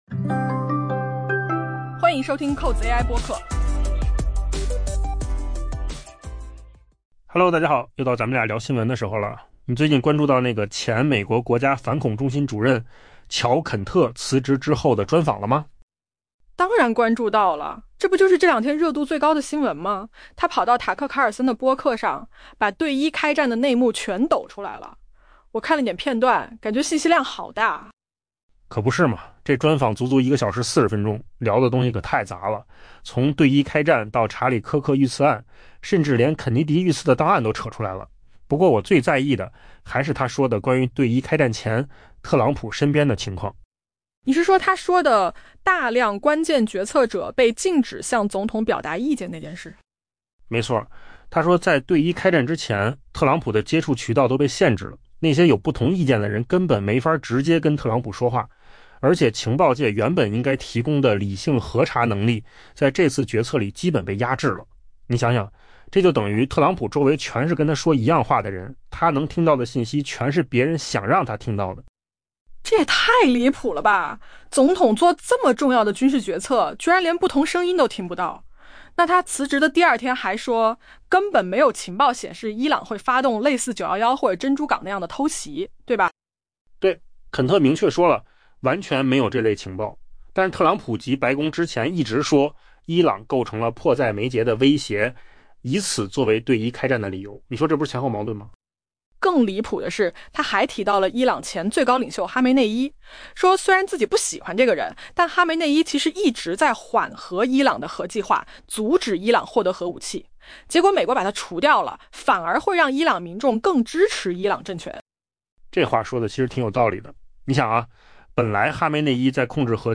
AI播客：换个方式听新闻 下载mp3 音频由扣子空间生成 前美国国家反恐中心主任乔・肯特（Joe Kent）周三接受前福克斯新闻主播、右翼播客主持人塔克・卡尔森（Tucker Carlson）的深度专访，这是他因担忧对伊朗开战而辞职后的首次公开表态。